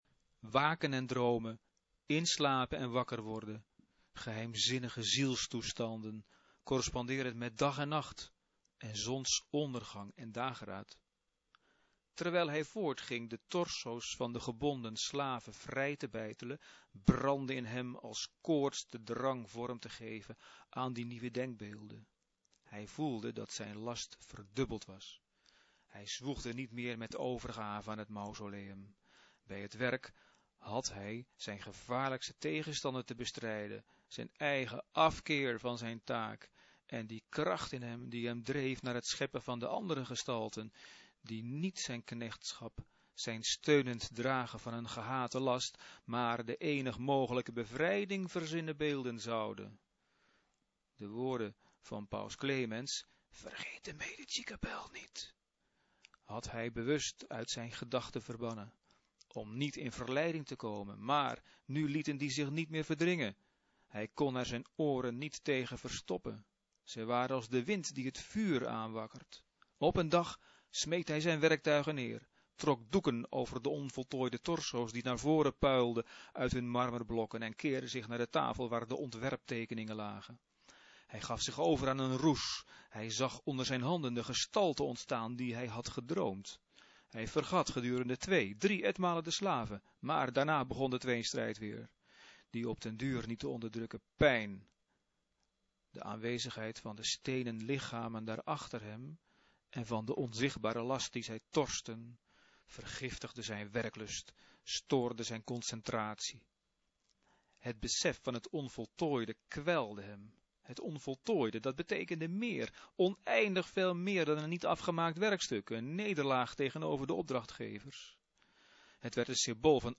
Betekent: het fragment wordt voorgelezen. (MP-3)